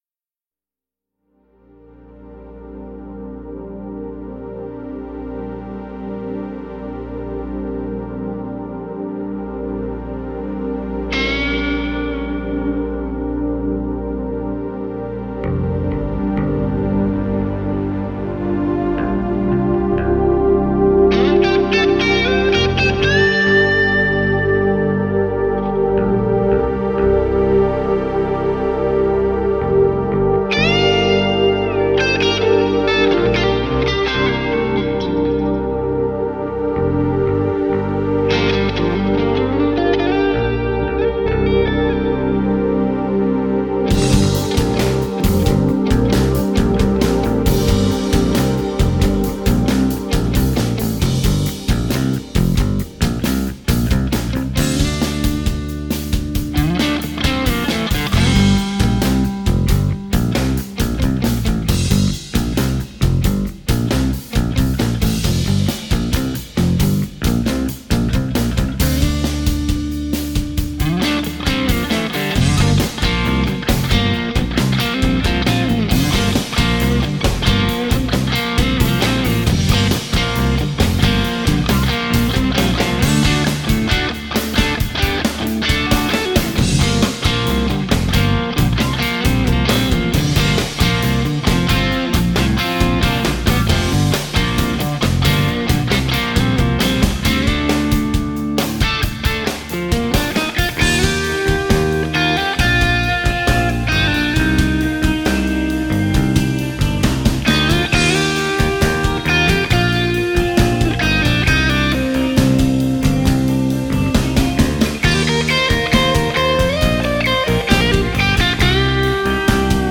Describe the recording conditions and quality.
All sound clips have been self produced there or with one of my mobile recording rigs. Most clips are actual songs I've played and recorded featuring the Highlighted Instrument / Amp etc. in a real world mix, not a straight up raw sounding demo.